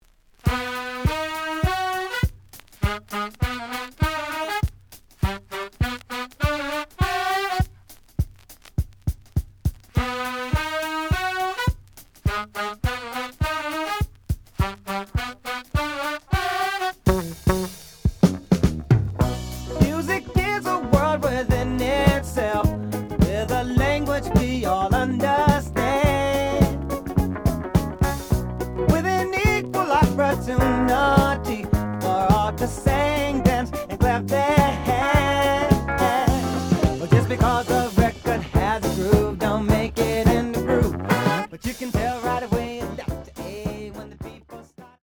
The audio sample is recorded from the actual item.
●Format: 7 inch
●Genre: Soul, 70's Soul